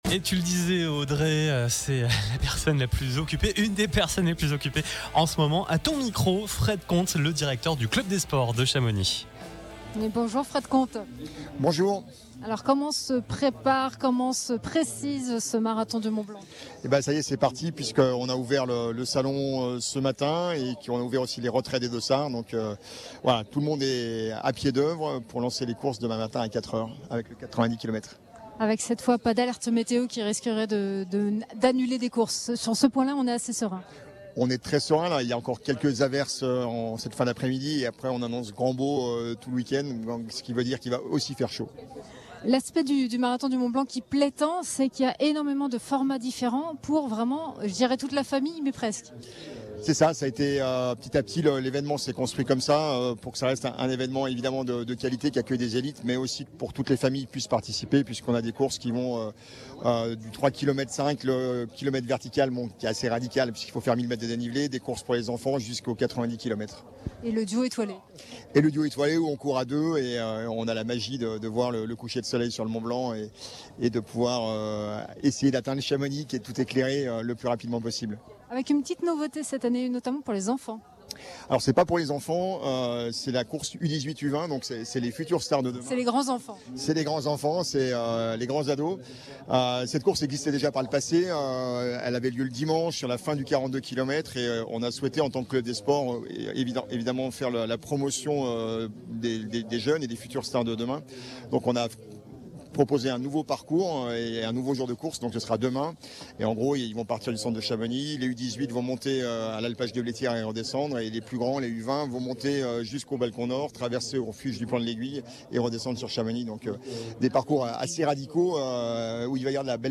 Duplex depuis le village exposants Place du Mont-Blanc
Ce jeudi après-midi, nous étions en direct en duplex depuis le village des exposants, pour une émission spéciale consacrée à cet événement sportif.